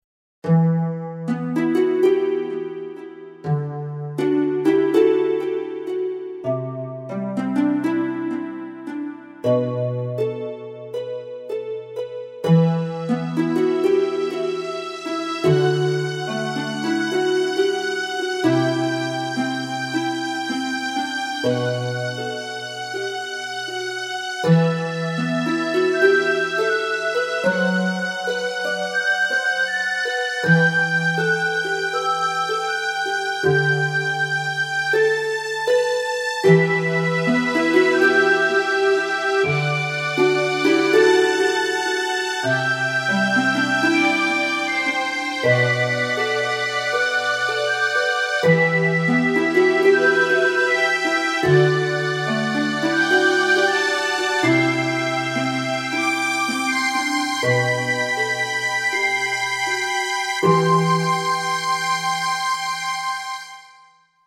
In passato ho composto alcuni brani musicali (completamente strumentali).
Tutti i brani sono stati composti con programmi per computer in formato MIDI.
• Un expander Yamaha MU-50
• Un expander Roland MT-32